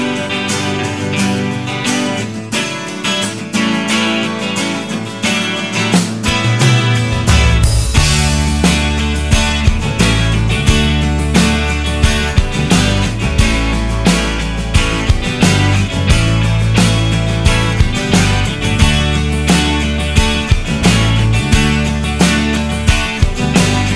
No Saxophone Country (Female) 4:10 Buy £1.50